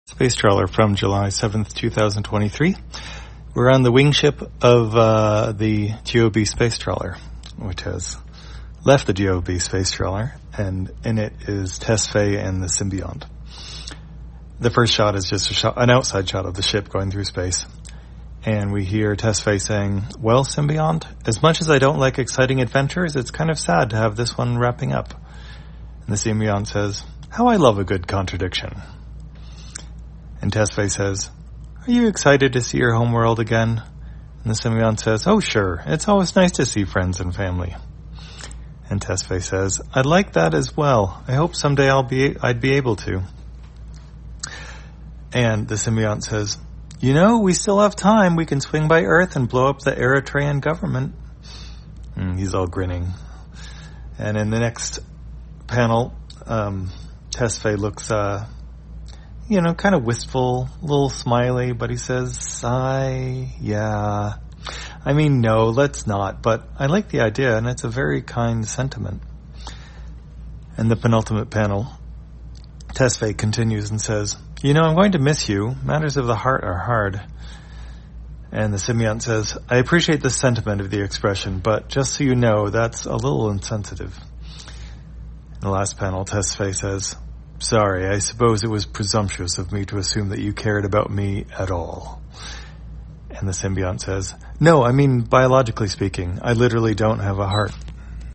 Spacetrawler, audio version For the blind or visually impaired, July 7, 2023.